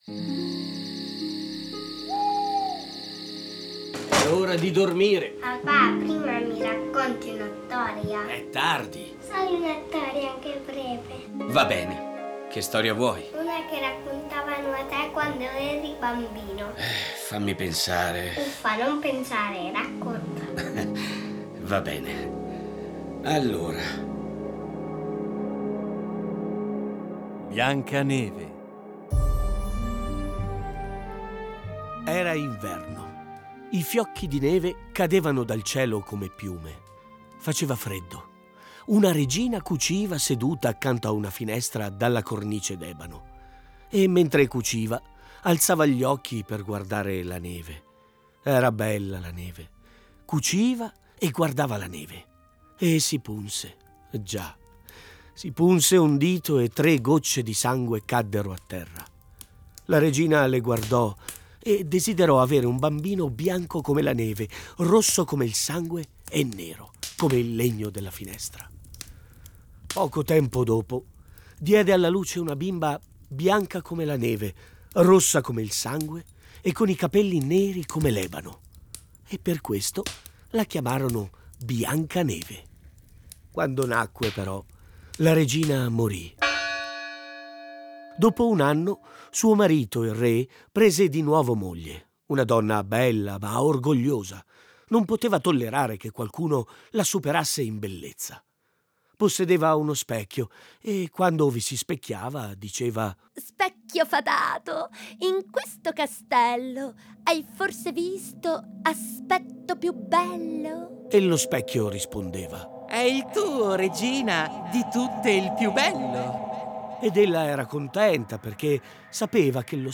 A partire dai testi originali un adattamento radiofonico per fare vivere i bambini storie conosciute, ma un po' dimenticate.